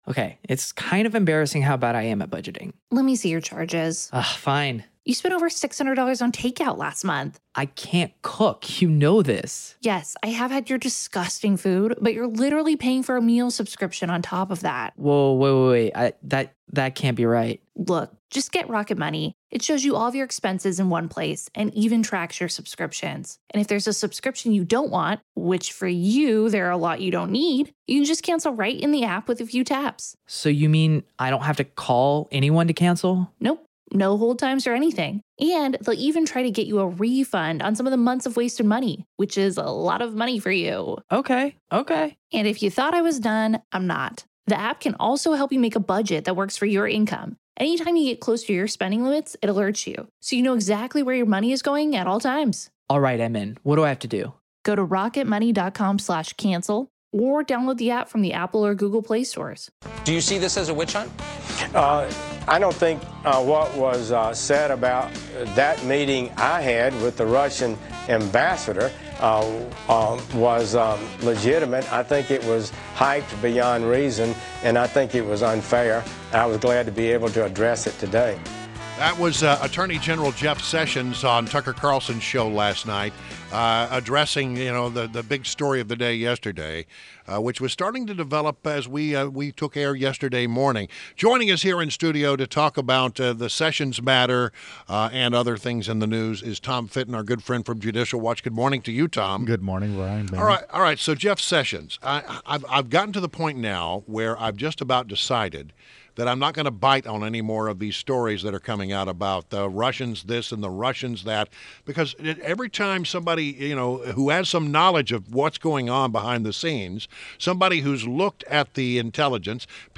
WMAL Interview - TOM FITTON - 03.03.17
INTERVIEW — TOM FITTON – PRESIDENT, JUDICIAL WATCH